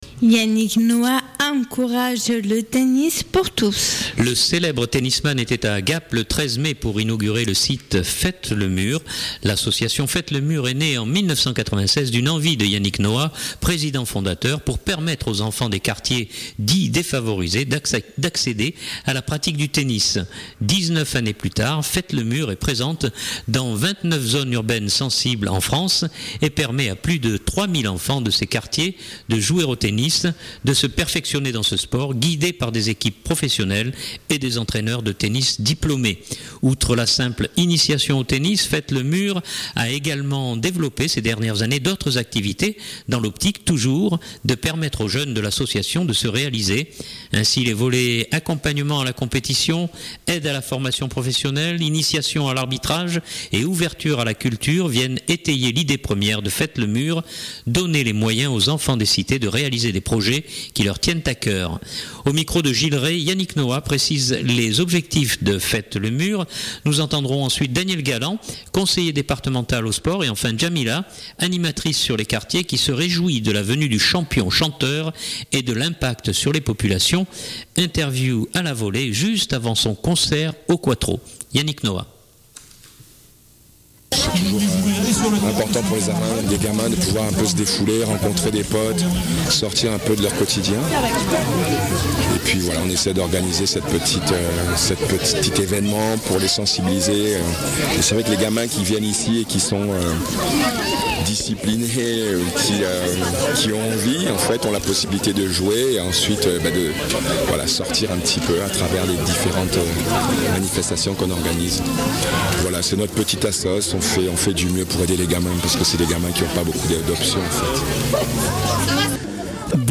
Interview à la volée juste avant son concert au Quattro.